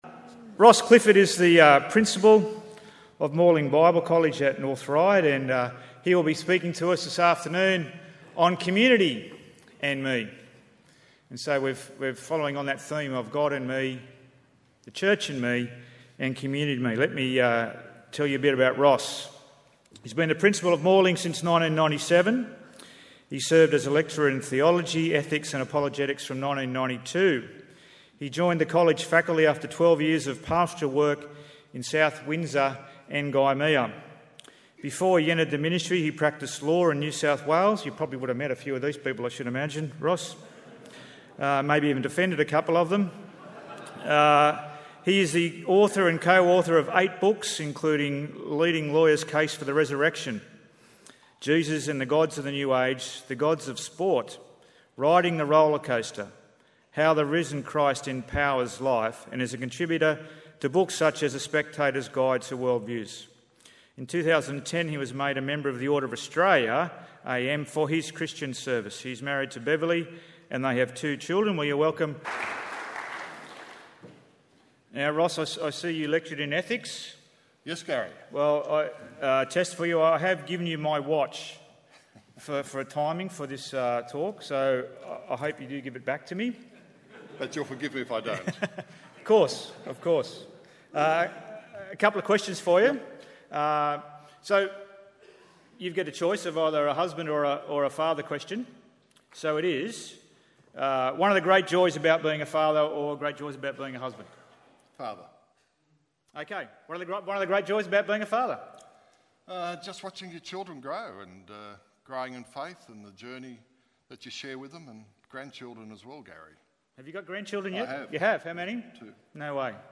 Keynote